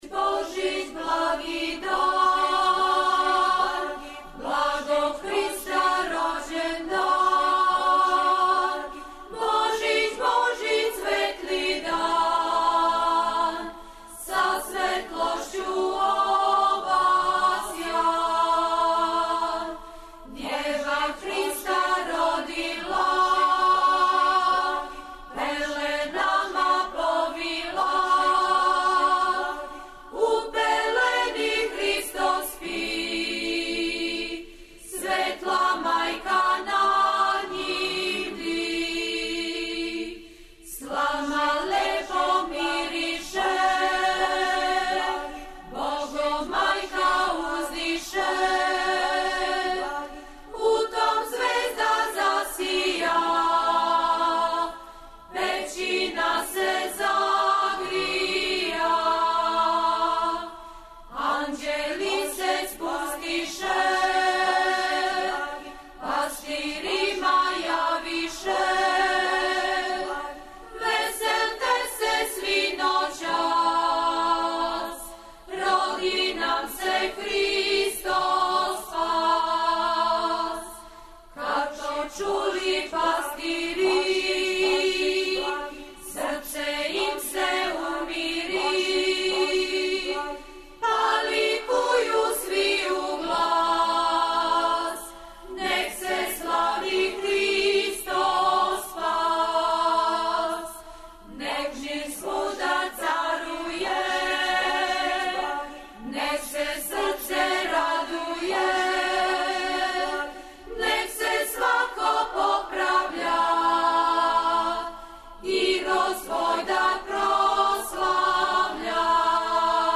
слушамо теренске записе традиционалних песама забележене у Доњем Банату